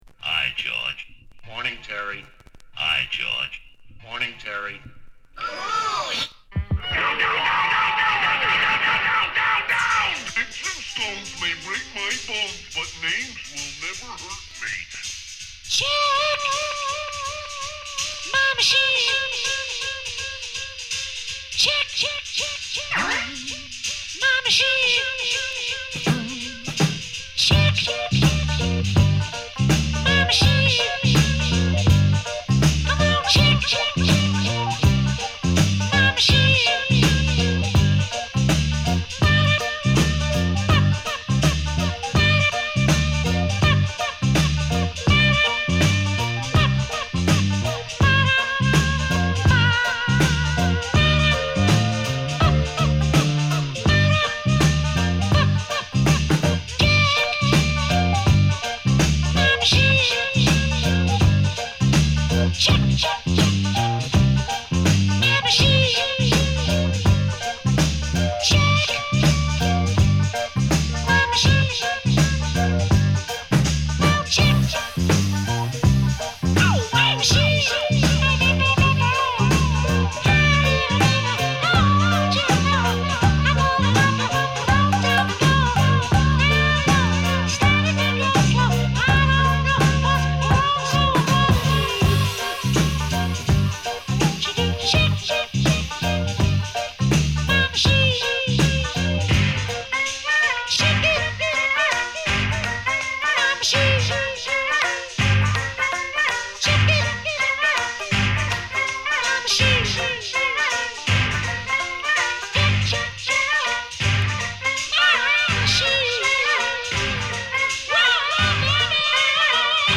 (Unreleased Instrumental)
Dubby , Mellow Groove
Rare Groove